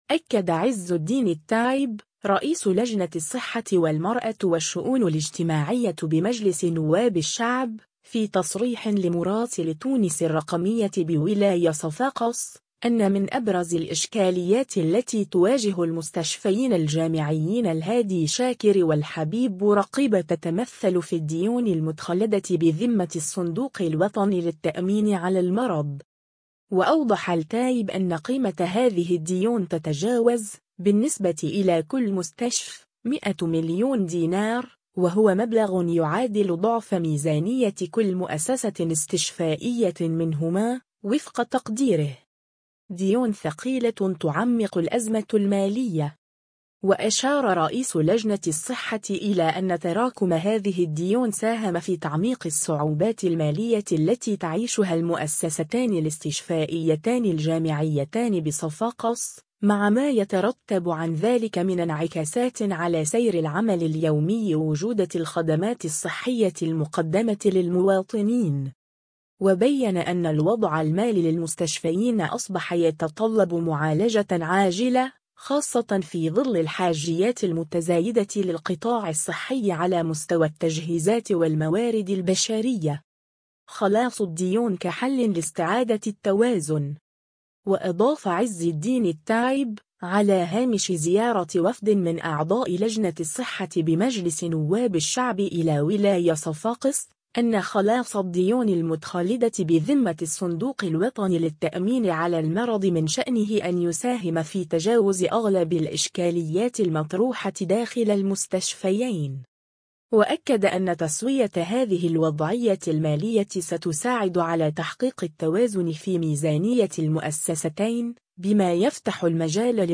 أكد عزالدين التايب، رئيس لجنة الصحة والمرأة والشؤون الاجتماعية بمجلس نواب الشعب، في تصريح لمراسل “تونس الرقمية” بولاية صفاقس، أن من أبرز الإشكاليات التي تواجه المستشفيين الجامعيين الهادي شاكر والحبيب بورقيبة تتمثل في الديون المتخلدة بذمة الصندوق الوطني للتأمين على المرض.